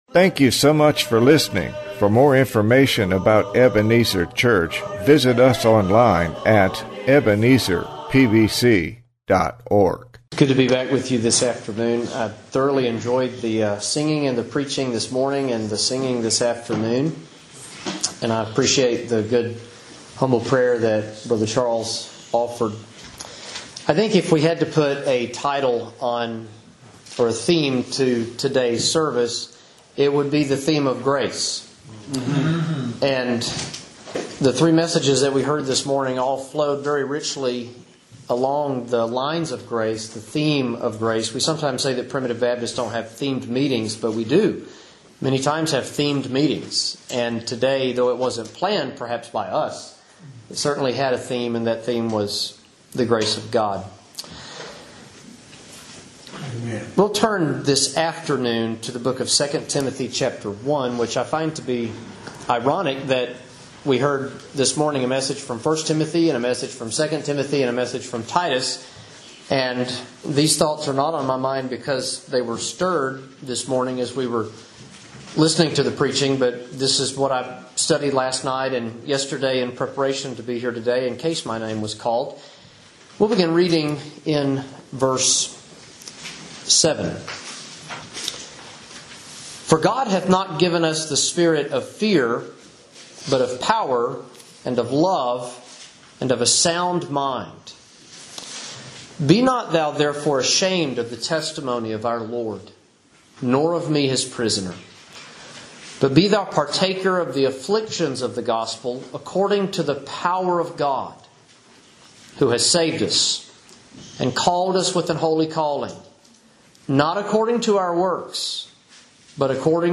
Preached Saturday afternoon at our October 2019 Annual Meeting